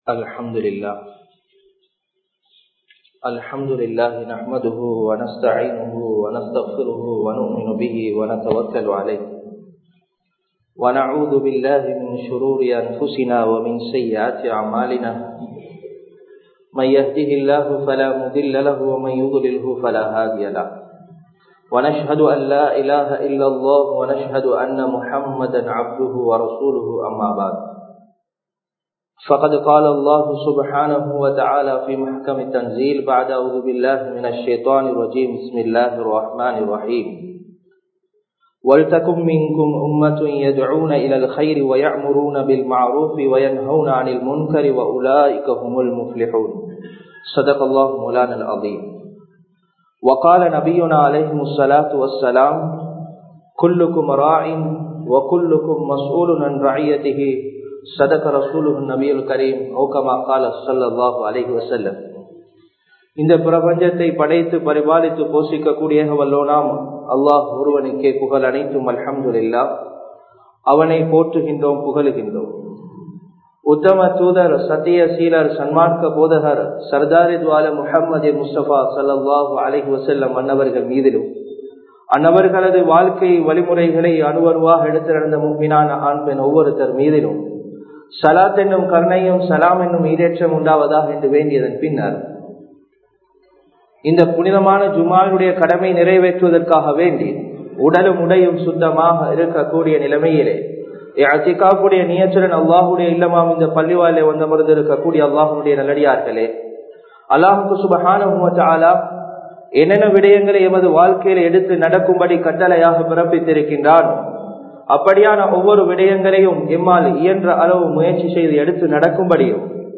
Sirantha Thalaivar Yaar? (சிறந்த தலைவர் யார்?) | Audio Bayans | All Ceylon Muslim Youth Community | Addalaichenai
Ilukkuwaththa Jumua Masjidh